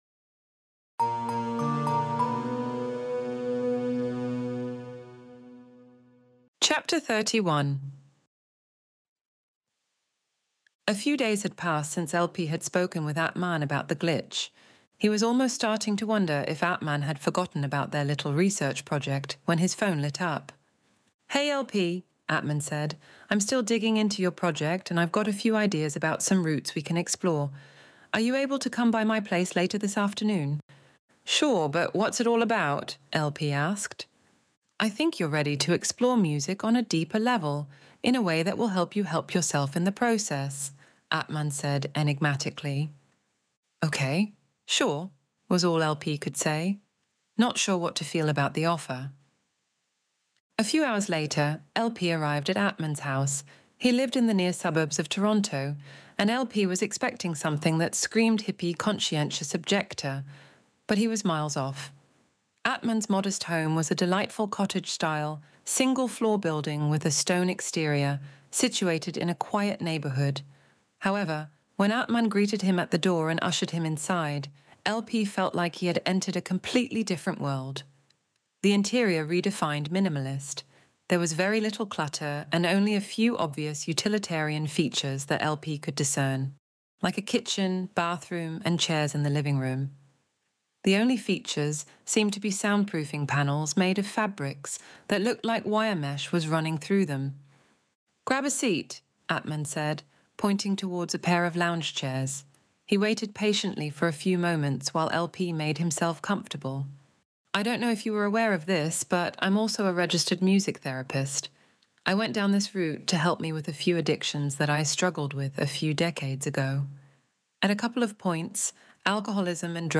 Extinction Event Audiobook Chapter 31